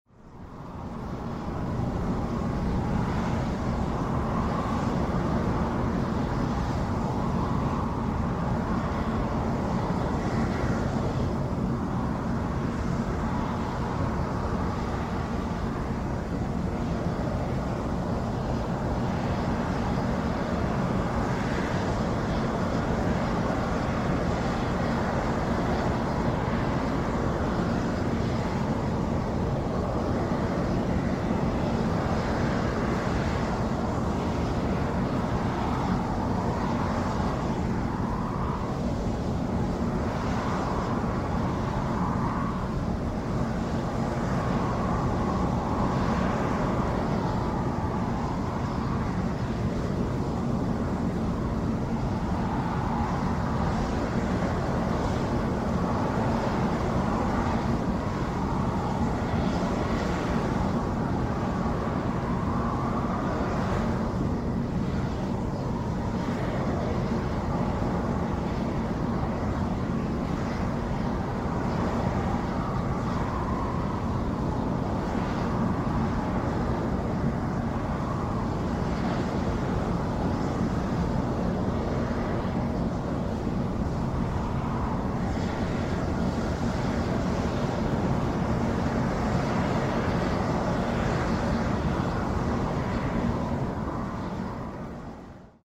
With temperatures above the boiling point, Beryl spring is one of the hottest thermal features in Yellowstone. This recording makes use of a binaural microphone system. If you listen with headphones, binaural recordings offer a 3-dimensional listening experience that goes way beyond simple stereo recordings.